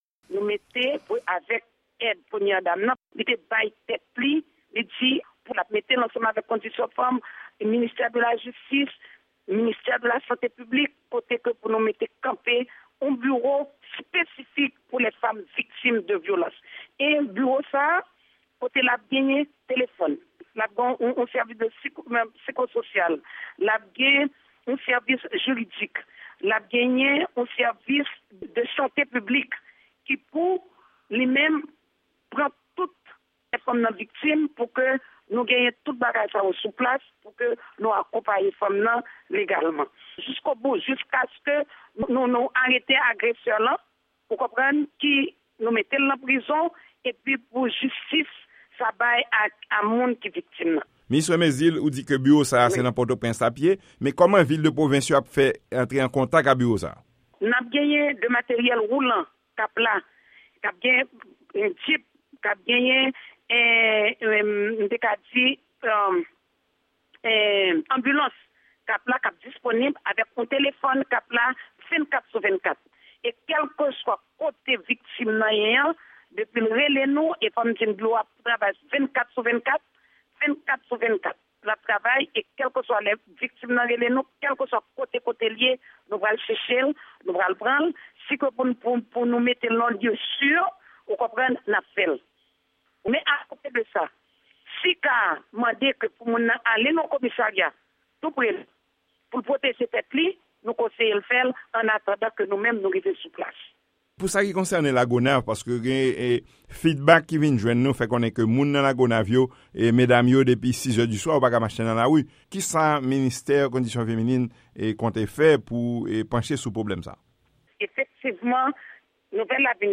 Deklarasyon Minis Yanick Mezile sou Vyolans sou Fanm ann Ayiti